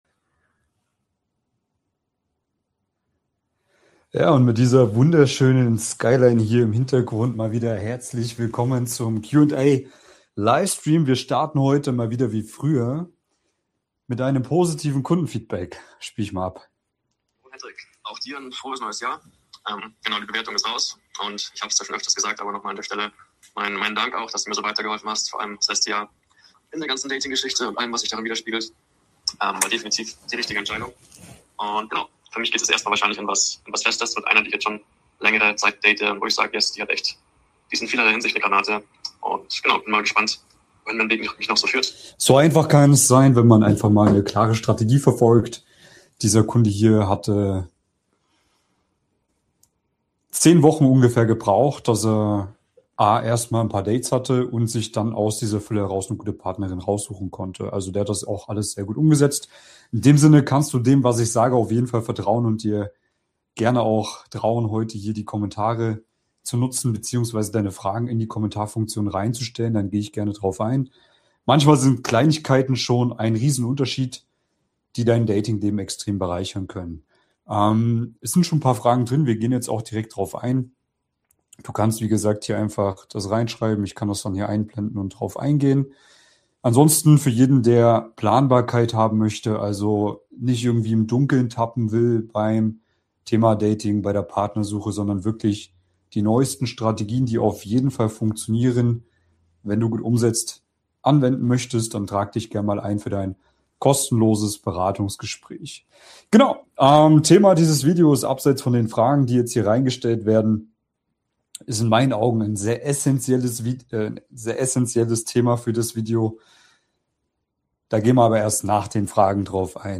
In diesem Livestream gehe ich live und ungefiltert auf eure Fragen rund um Dating, Frauen verstehen, Mann-Frau-Dynamiken und moderne Partnersuche ein.